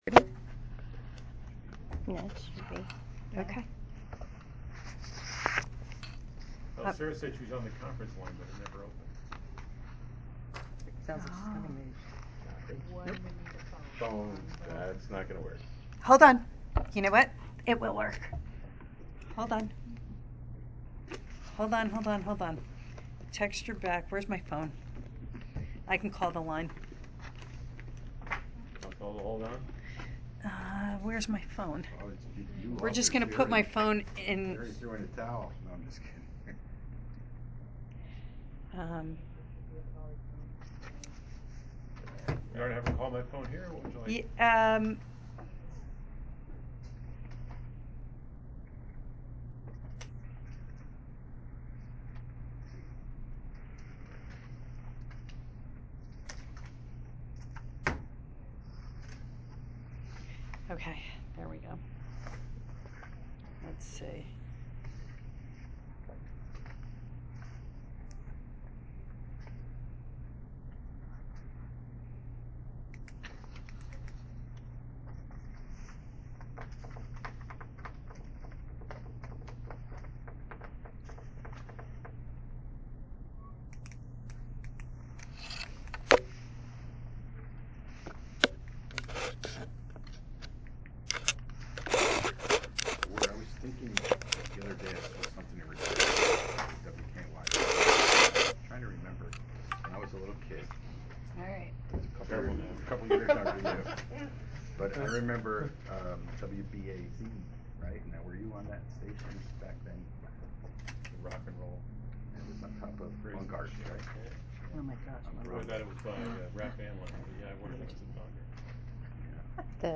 March 2, 2020 UCEDA MEETING RECORDING.MP3